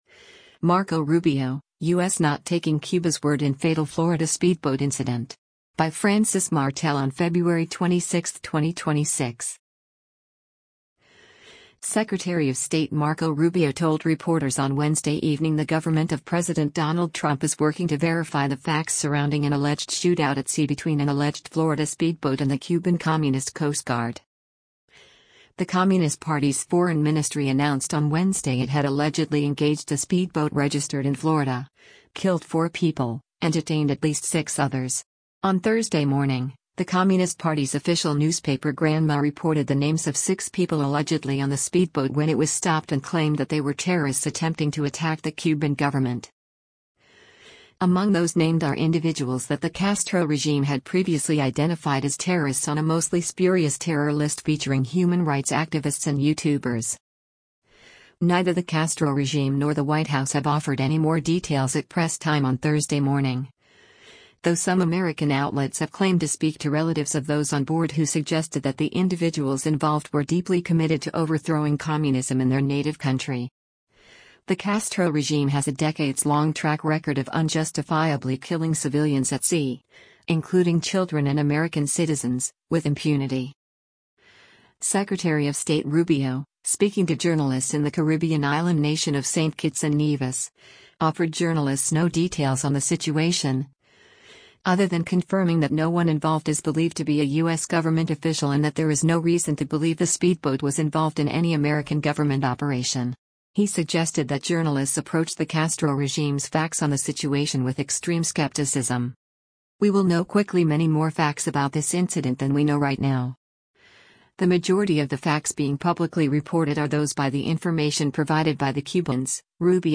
Secretary of State Rubio, speaking to journalists in the Caribbean island nation of St. Kitts and Nevis, offered journalists no details on the situation, other than confirming that no one involved is believed to be a U.S. government official and that there is no reason to believe the speedboat was involved in any American government operation.